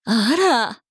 大人女性│女魔導師│リアクションボイス│商用利用可 フリーボイス素材 - freevoice4creators
突然のことに驚く